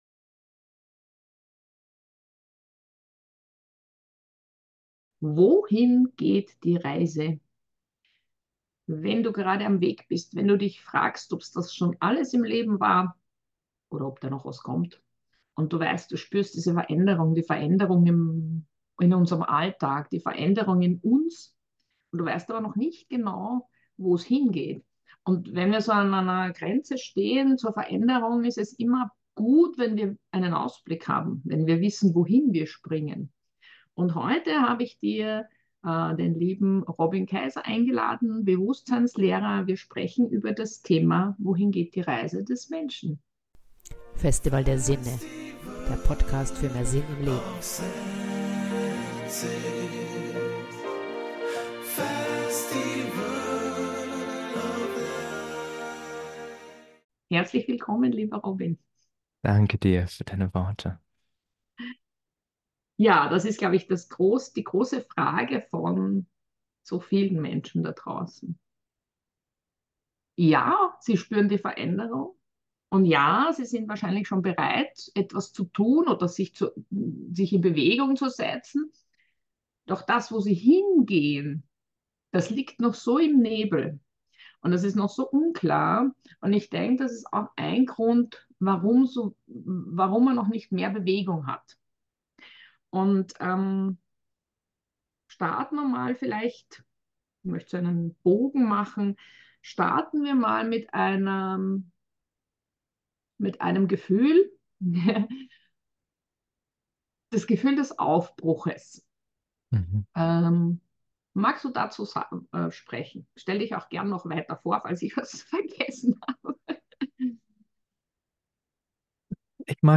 Im Gespräch mit Bewusstseinsforscher